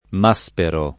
m#Spero] cogn. — anche francesizz. [fr. maSper1] come cogn. dell’egittologo Gaston M. (1846-1914) e del sinologo Henri M. (1883-1945) — solo con pn. it. il medico e letterato Paolo M. (1811-96)